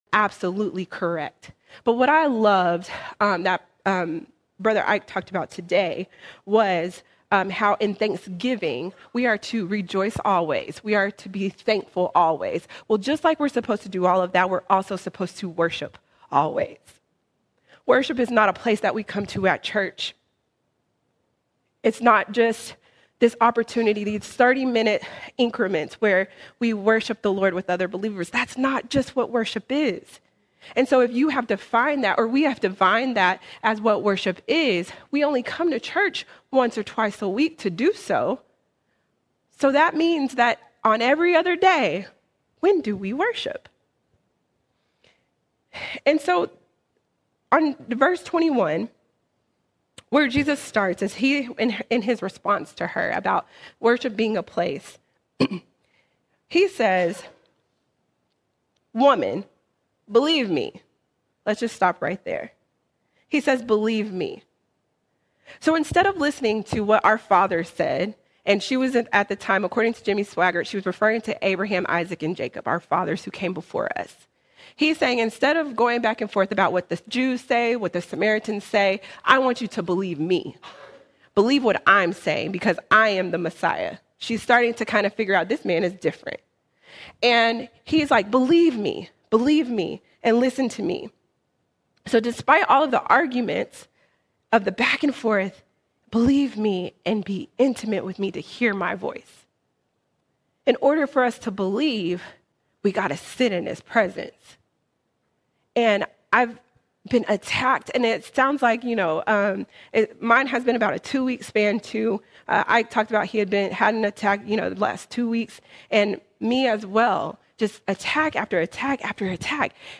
24 November 2025 Series: Sunday Sermons All Sermons Worship Without Walls Worship Without Walls True worship isn’t confined to a building but flows from the heart wherever you are.